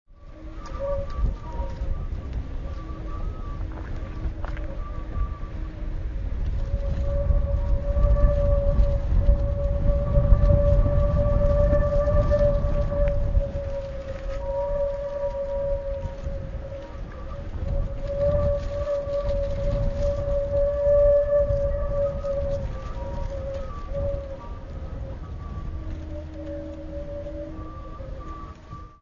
Into these openings the breath of the wind is blowing in like into many flutes, and, depending on the wind-direction, you can listen to endless random sequences of harmonic cords, ascending and falling following the breath of nature.
Here’s another exemple of a recent sound-recording of a protection fence against pig’s flue dating from Oct. 2025
Whistling holes in a fence Segelflugplatz Weinheim 2025